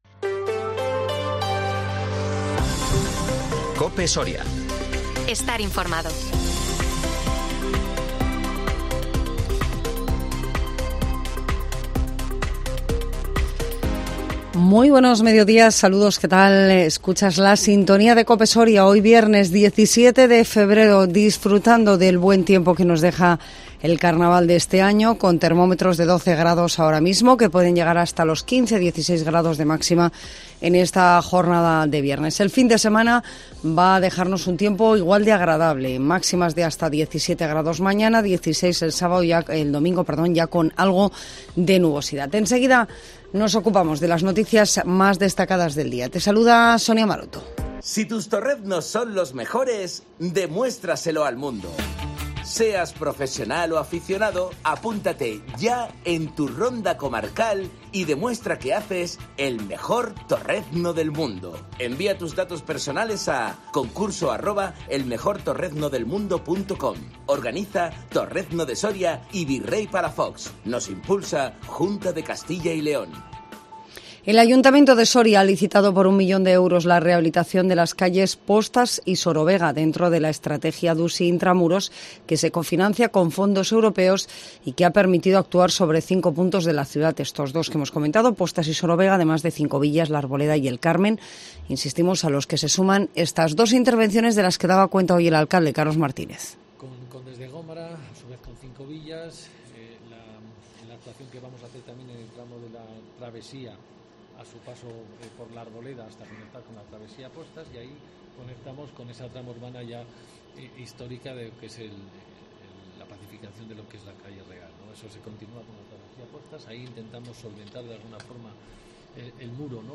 INFORMATIVO MEDIODÍA COPE SORIA 17 FEBRERO 2023